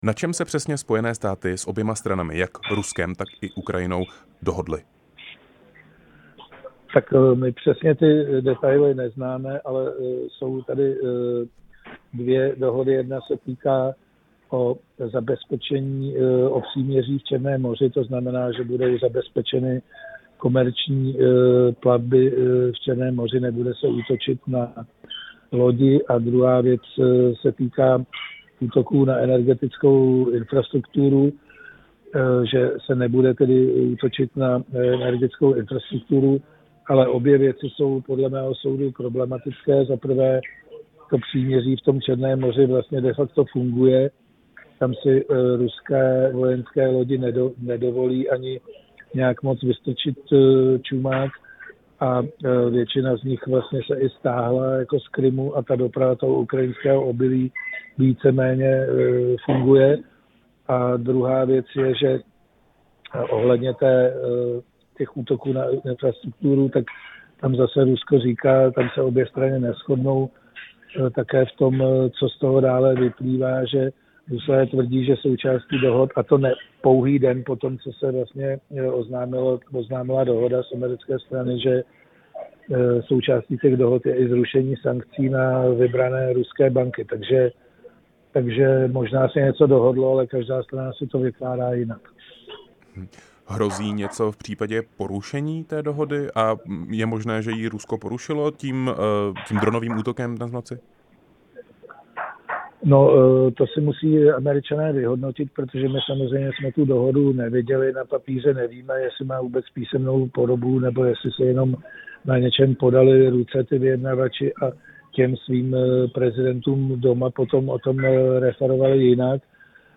Tam Washington uzavřel dvě samostatné dohody s Ukrajinou a Ruskem o příměří v Černém moři. Ve vysílání Rádia Prostor jsme téma probrali s komentátorem a analytikem Janem Macháčkem.
Rozhovor s komentátorem a analytikem Janem Macháčkem